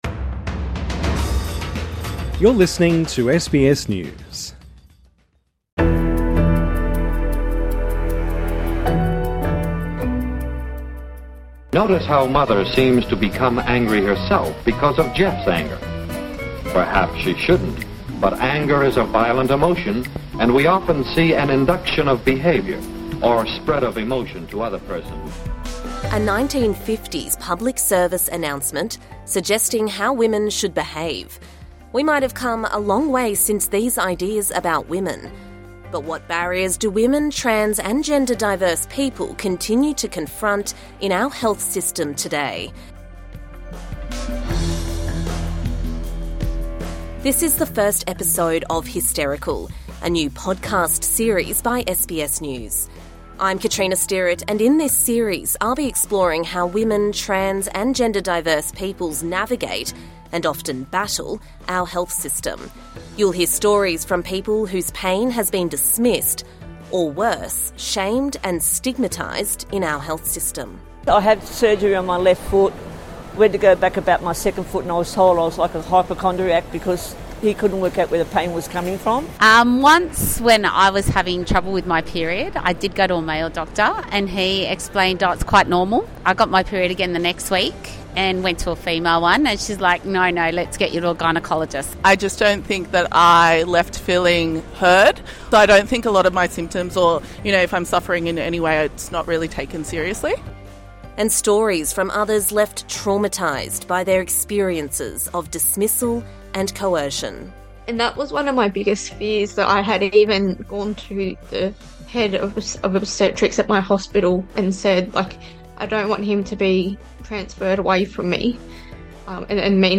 A 1950s public service announcement suggesting how women should behave.